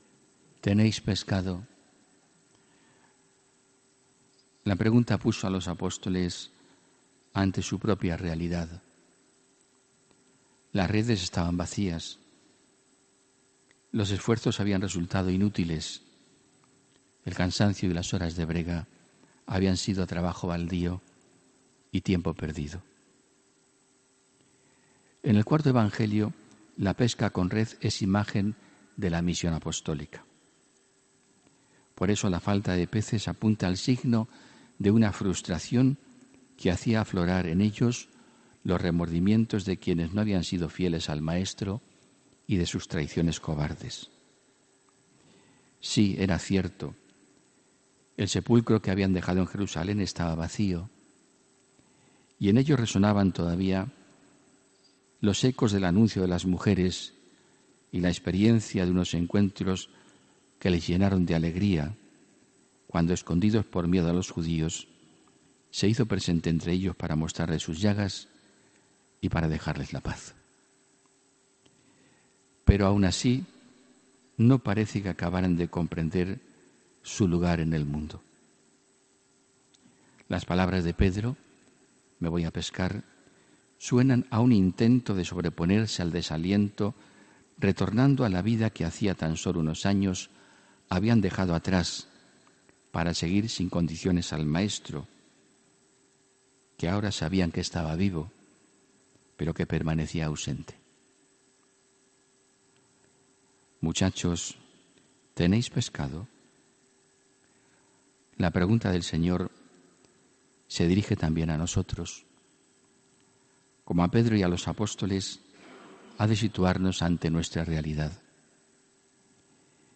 HOMILÍA 5 MAYO 2019